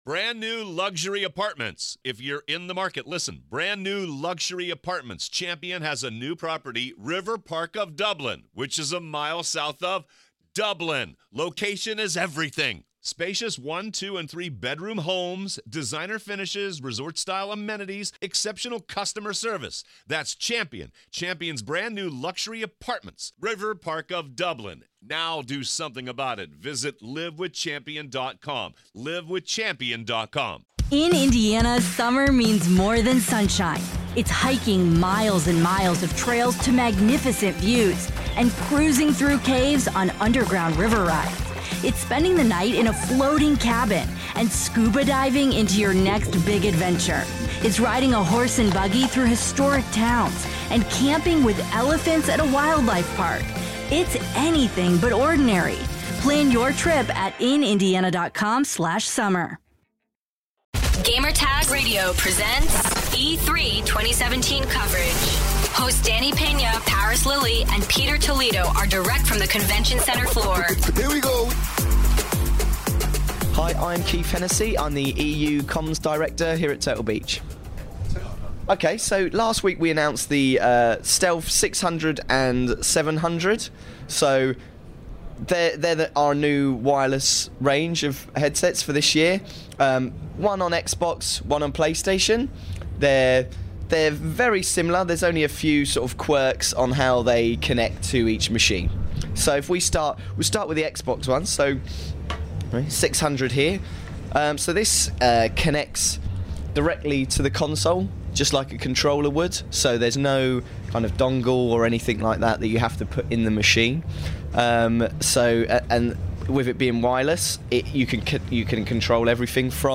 E3 2017: Turtle Beach Interview